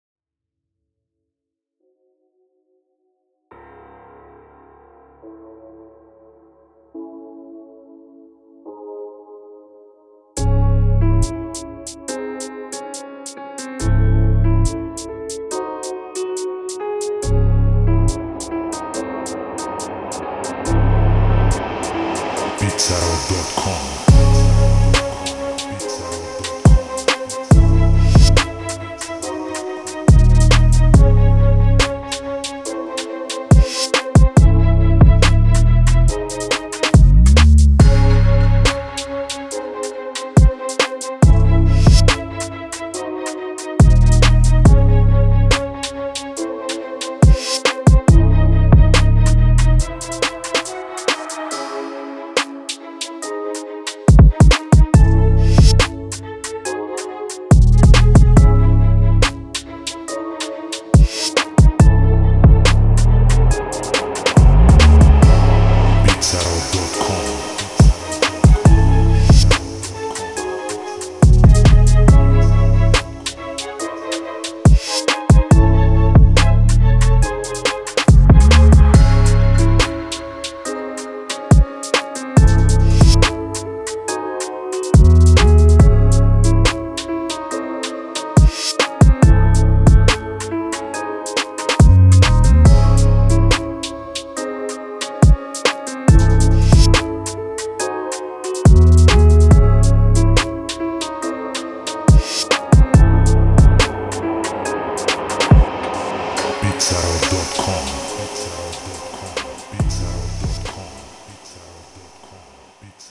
دسته و ژانر: Drill
سبک و استایل: گنگ،اجتماعی
سرعت و تمپو: 140 BPM